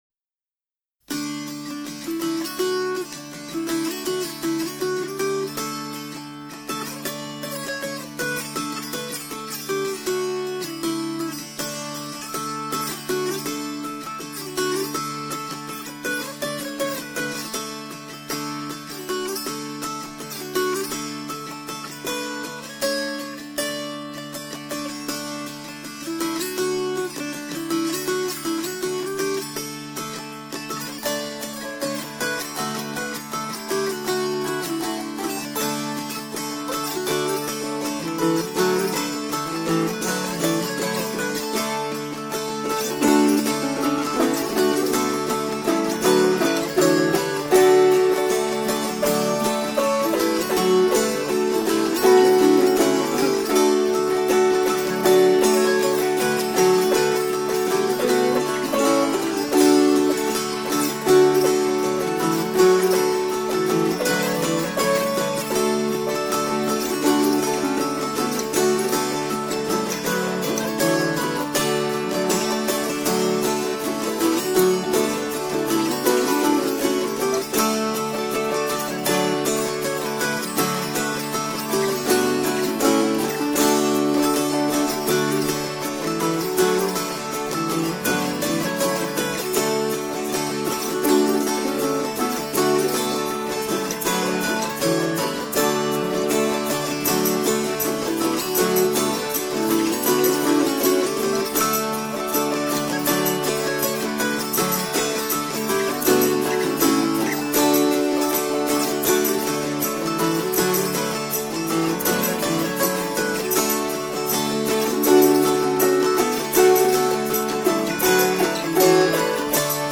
Mid/Uptempo inst. Acoustic folk feel Joyful